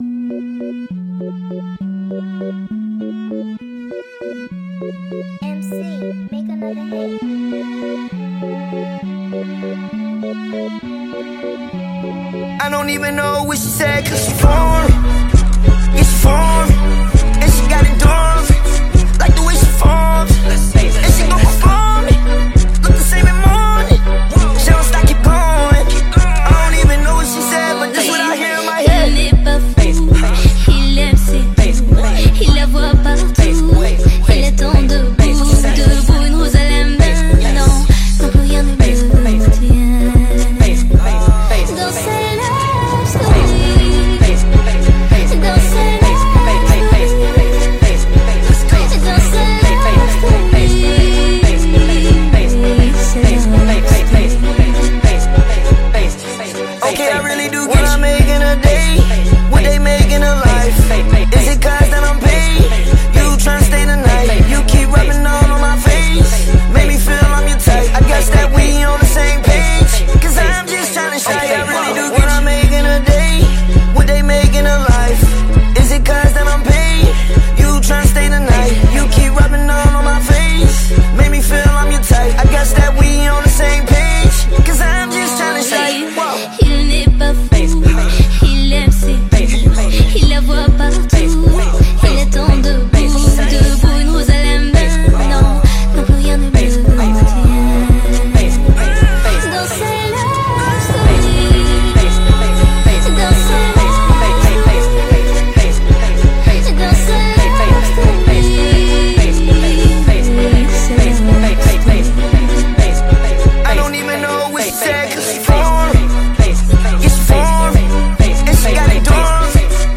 high-energy track